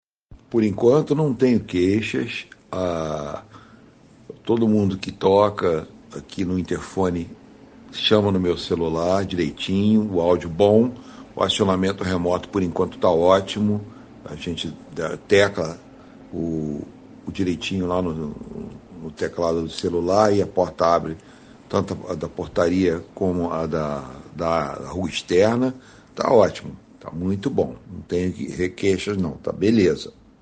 DEPOIMENTO DE VOZ DE CLIENTES